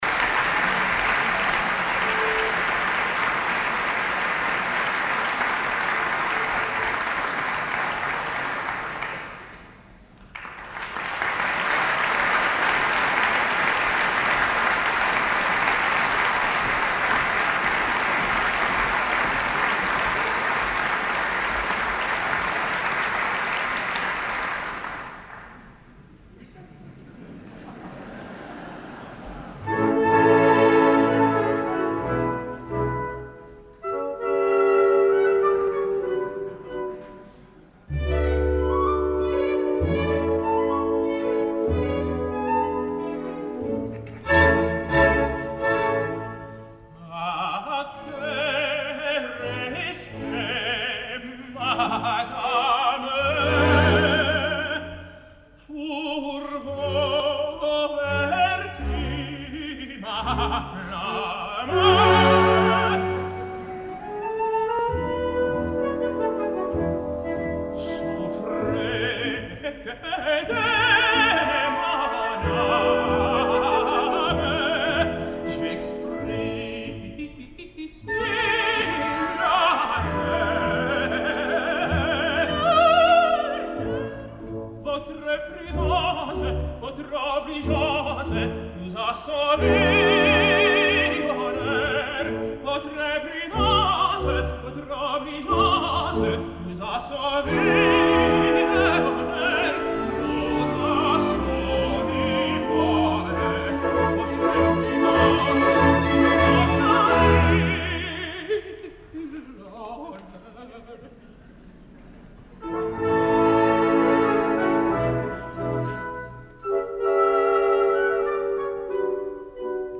BEL CANTO
cantants nord-americans
soprano
tenor